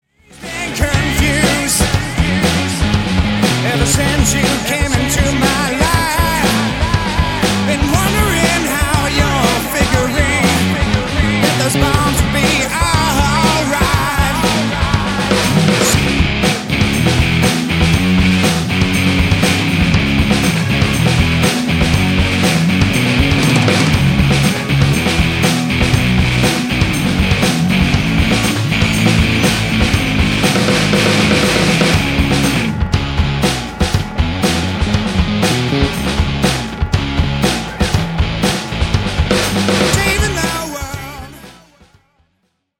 (Note: The song sounds significantly different here because I added a drum machine to tie our parts together.)
I went with a standard rock song arrangement, with verses, choruses, and a solo break.